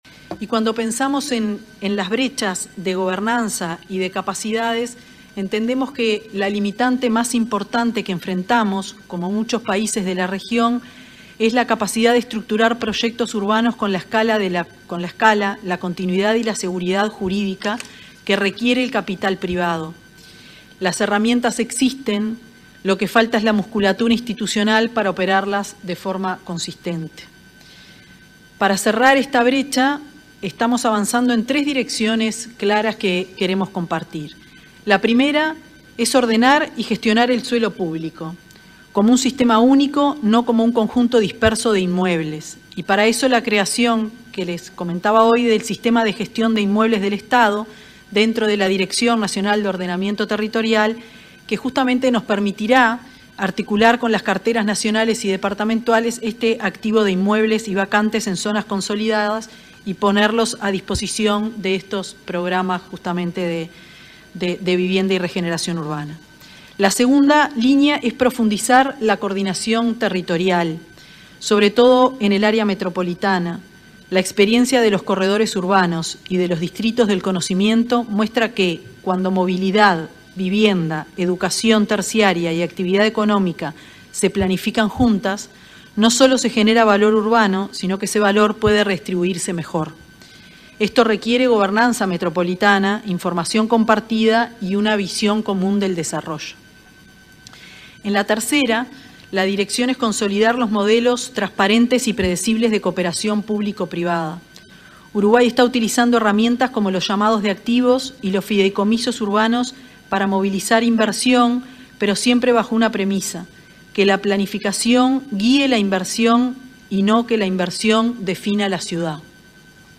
Palabras de la ministra de Vivienda, Tamara Paseyro
En ocasión de la 34.ª Asamblea General de Ministros y Autoridades Máximas de la Vivienda y el Urbanismo de América Latina y el Caribe, en Bridgetown,